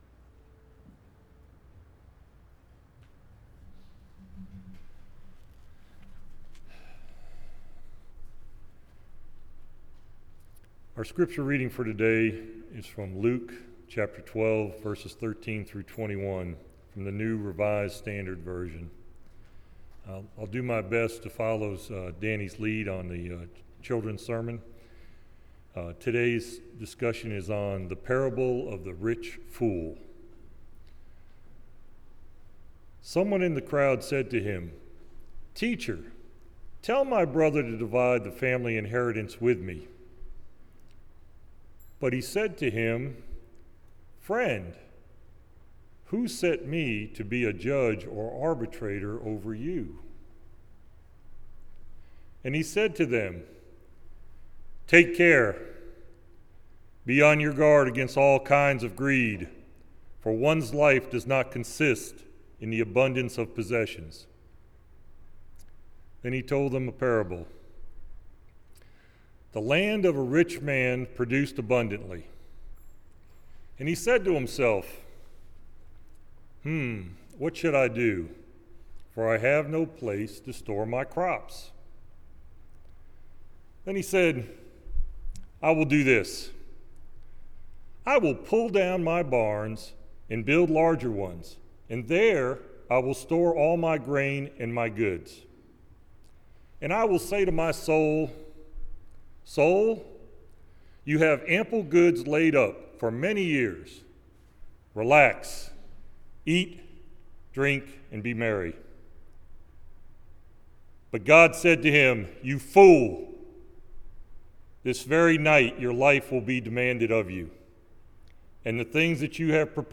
St. Charles United Methodist Church Sermons